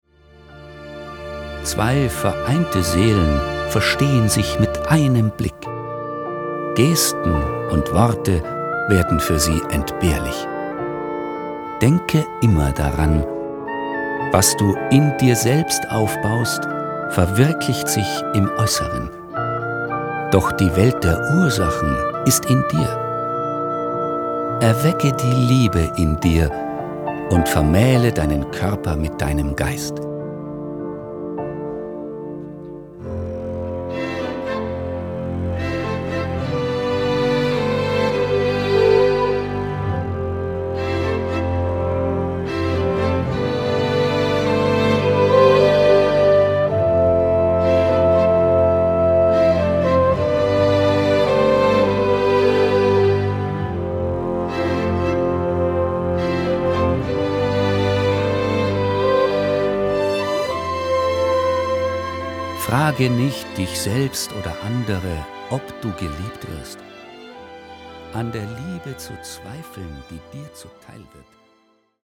Ein Hörspiel über die Liebe, das erfolgreich 2022 mit der Crowd finanziert wurde.